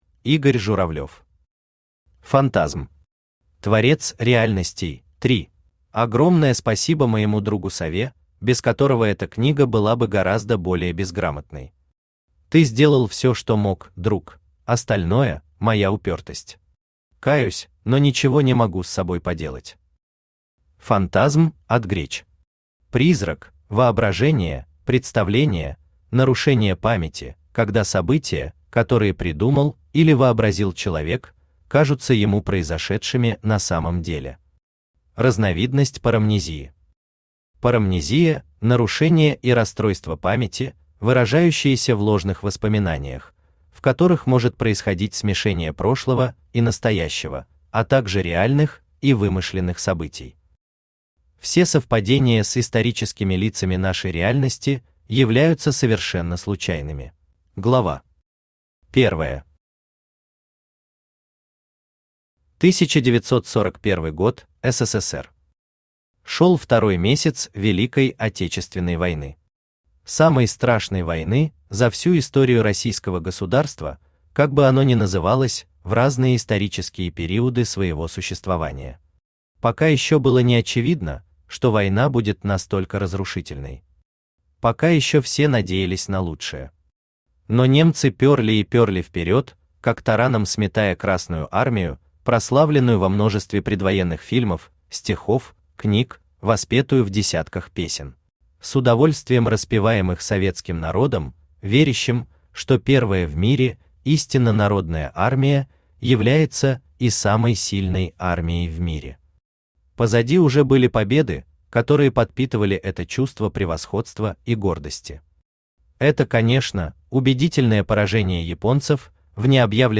Аудиокнига Фантазм. Творец реальностей – 3 | Библиотека аудиокниг
Творец реальностей – 3 Автор Игорь Журавлев Читает аудиокнигу Авточтец ЛитРес.